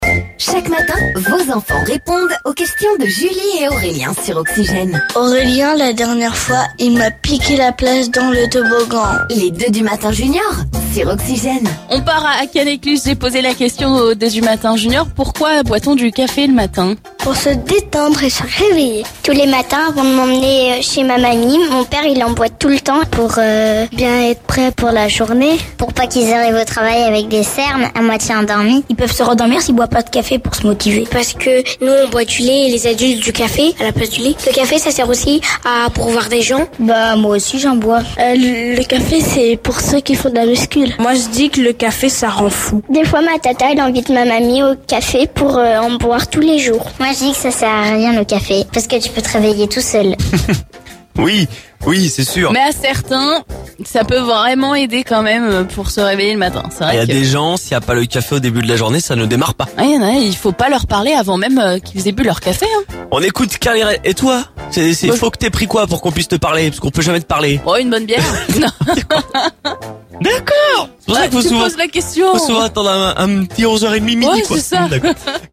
Écouter le podcast Télécharger le podcast Ecoutons les enfants Seine-et-Marnais nous expliquer pourquoi on boit du café...